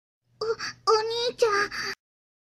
o-onii-chan-sound-effect_TMUP17M.mp3